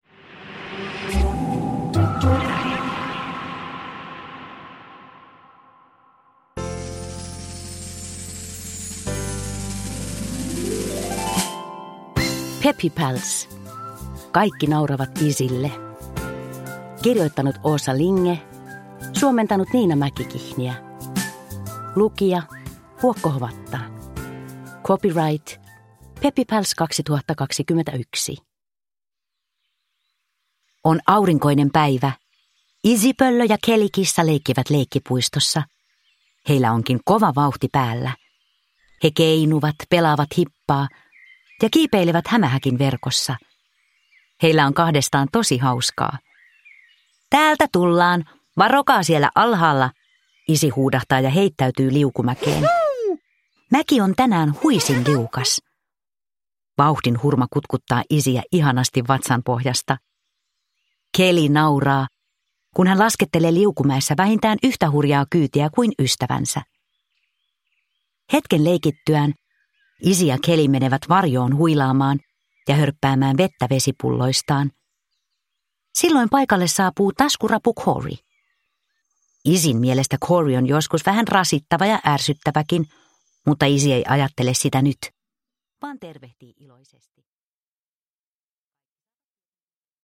Peppy Pals: Kaikki nauravat Izzylle – Ljudbok – Laddas ner